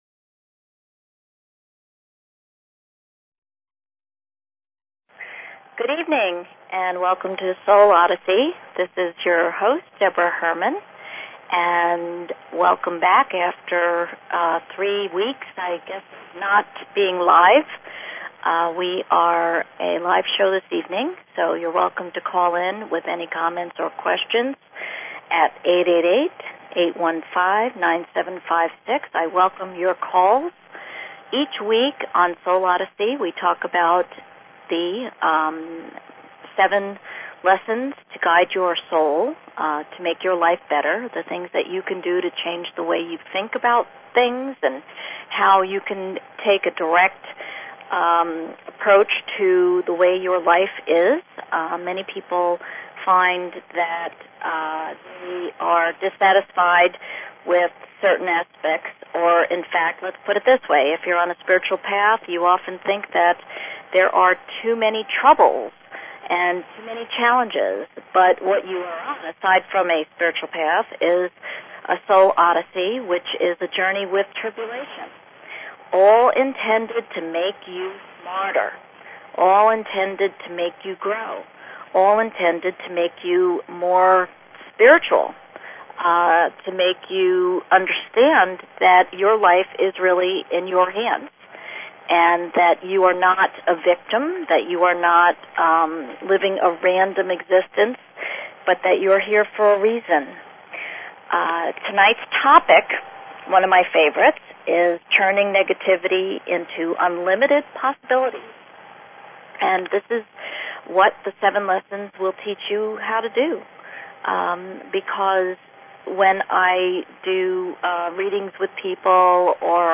Talk Show Episode, Audio Podcast, Soul_Odyssey and Courtesy of BBS Radio on , show guests , about , categorized as
This weeks topic is " replacing the negative with unlimited possibilities." Live call in second half of the hour.